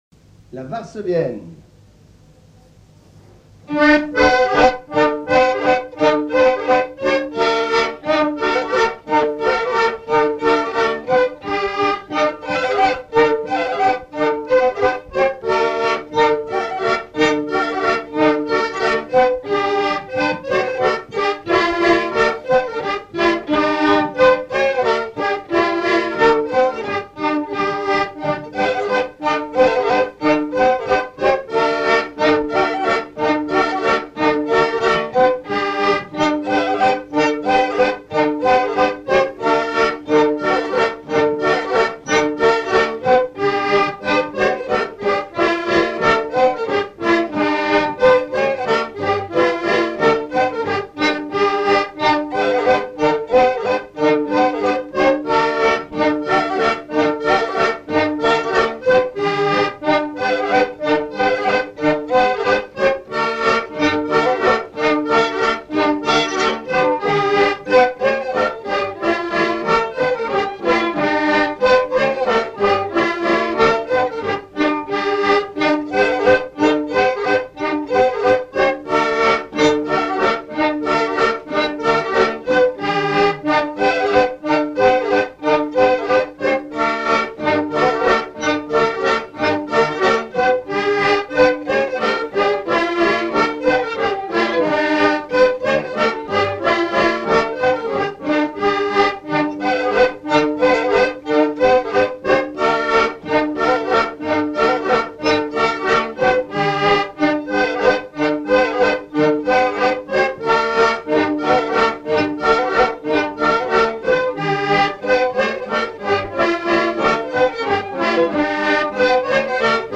Laforte : Votre petit chien madame - V, F-159 Coirault : non-référencée - 141** Thème : 1074 - Chants brefs - A danser Résumé : Votre chien madame, votre chien Madame, Mord-t-il ?
danse : varsovienne
Pièce musicale inédite